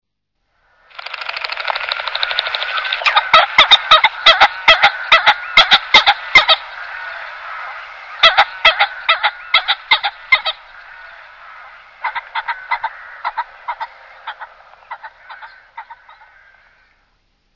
fagiano c.wav